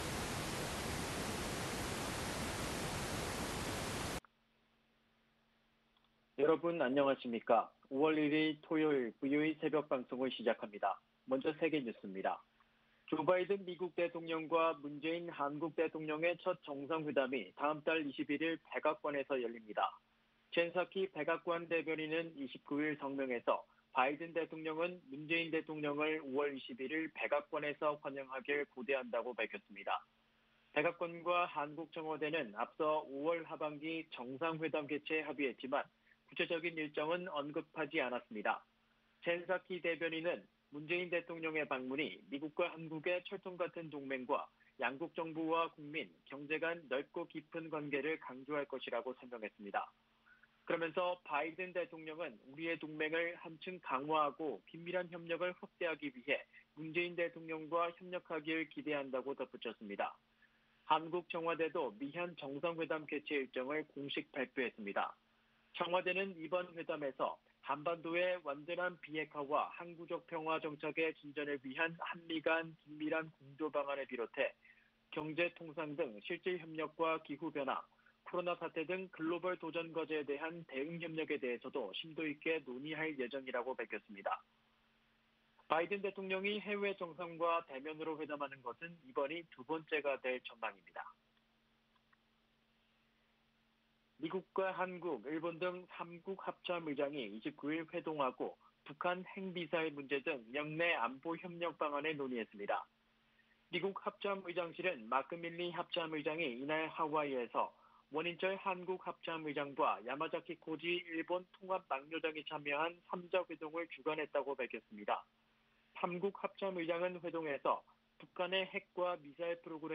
VOA 한국어 '출발 뉴스 쇼', 2021년 5월 1일 방송입니다. 조 바이든 미국 대통령과 문재인 한국 대통령의 정상회담이 다음달 21일 워싱턴에서 열린다고 미국 백악관이 밝혔습니다. 미국이 신종 코로나바이러스 감염증 백신을 제공하는 형태로 대북 지원이 가능할 것이라는 제안이 나왔습니다.